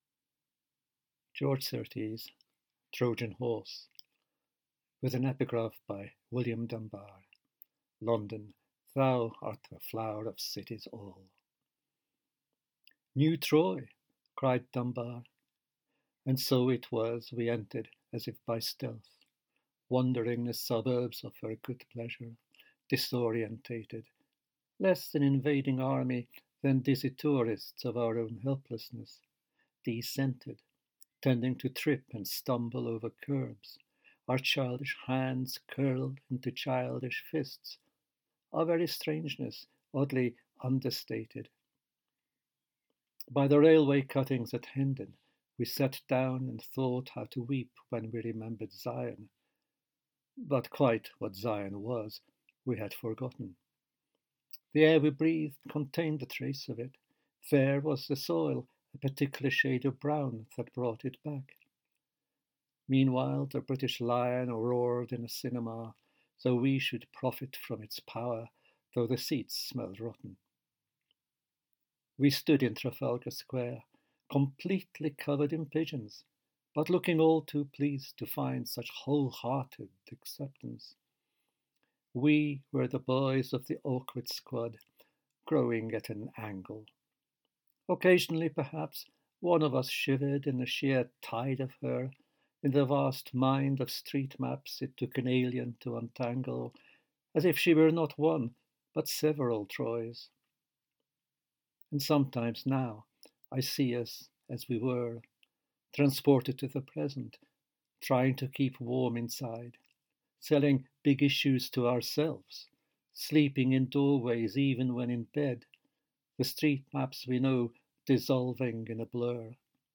George Szirtes reads his poem A Trojan Horse in Trafalgar Square
George-Szirtes-reads-his-poem-A-Trojan-Horse-in-Trafalgar-Square.mp3